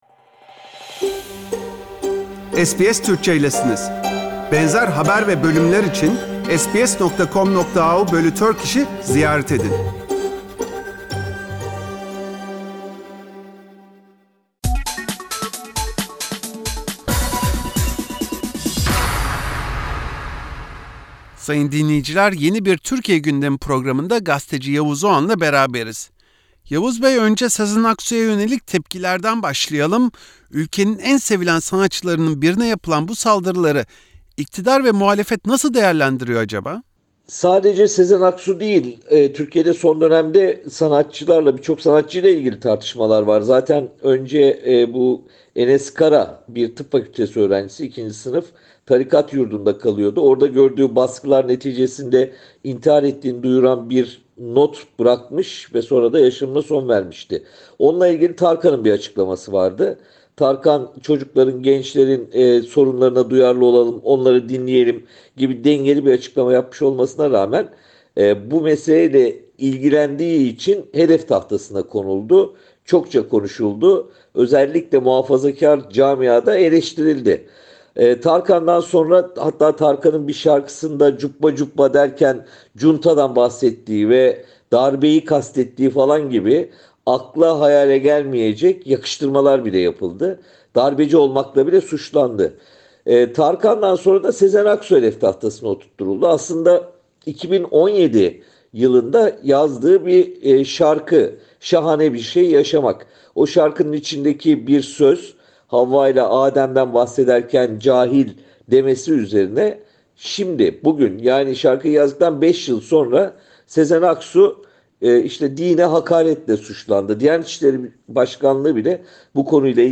Gazeteci Yavuz Oğhan SBS Türkçe için yaptığı değerlendirmede, Sezen Aksu’ya yönelik eleştirilerin gündemi değiştirmeyi hedeflediğini ifade etti.